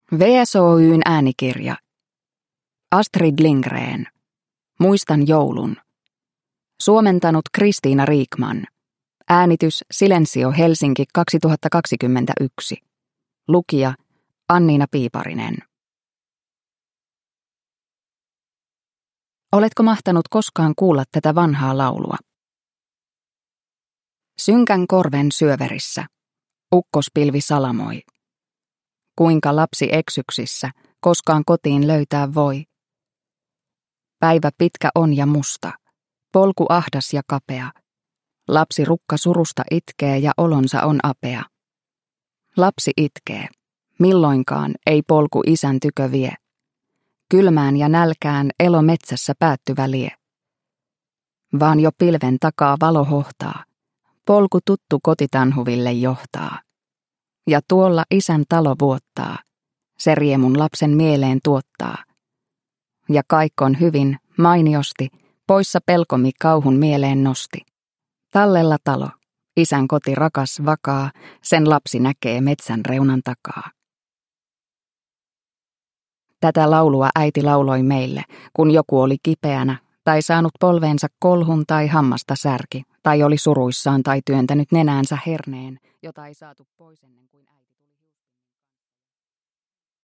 Muistan joulun – Ljudbok – Laddas ner